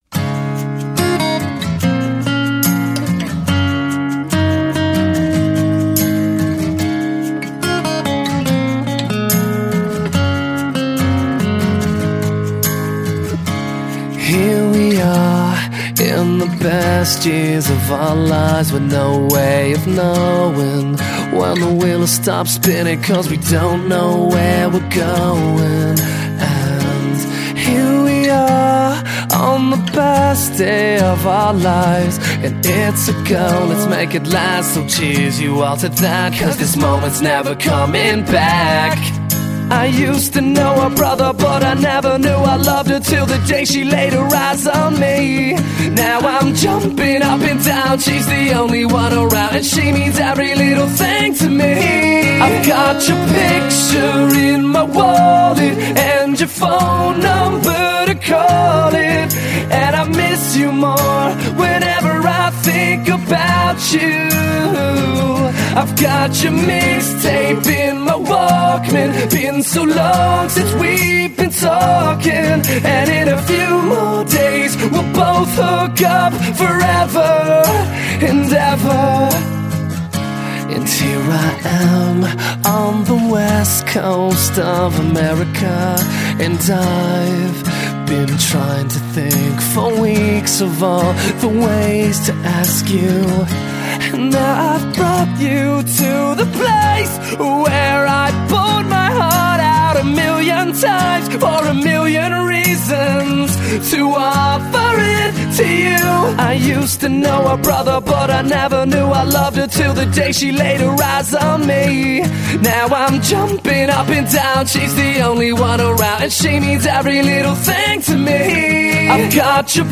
Christian pop punk duo